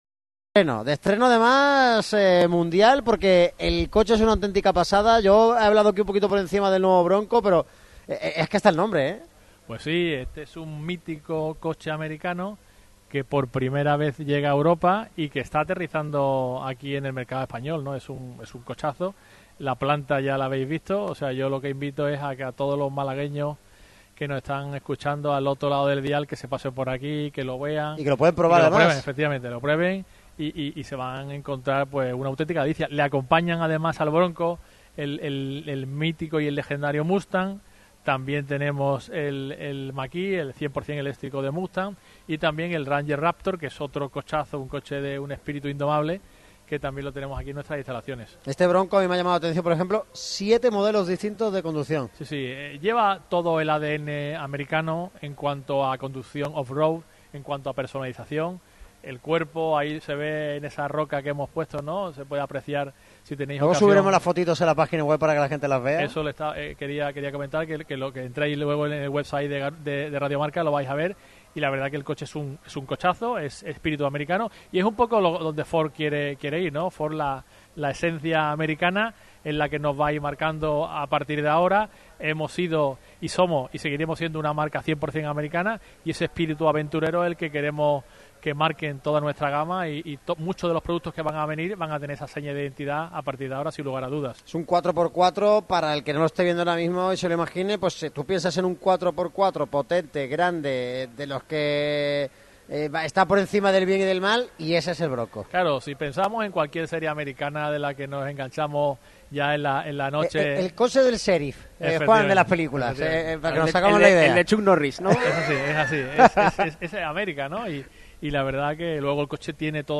Esta ocasión fue con motivo de la presentación de la nueva gama de coches, Bronco.
Además, escuchamos las palabras de Ibon tras el partido en rueda de prensa.